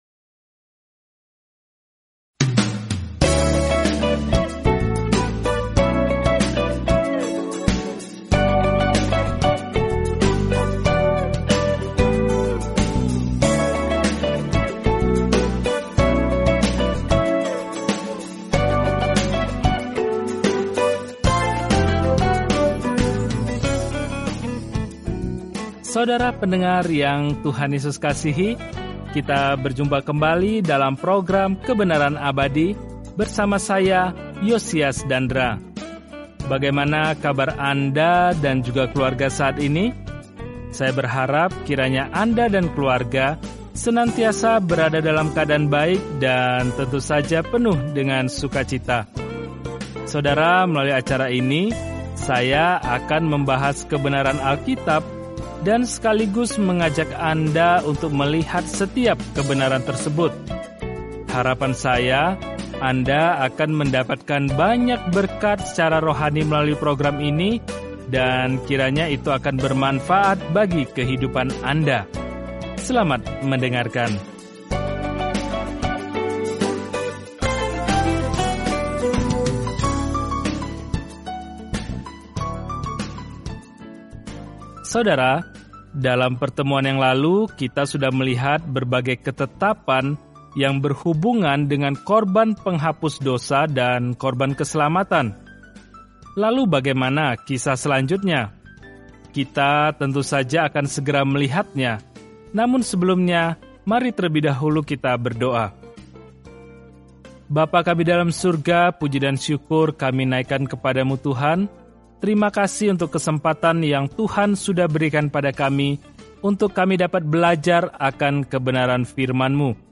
Dalam ibadah, pengorbanan, dan rasa hormat, Imamat menjawab pertanyaan itu bagi Israel zaman dahulu. Jelajahi Imamat setiap hari sambil mendengarkan studi audio dan membaca ayat-ayat tertentu dari firman Tuhan.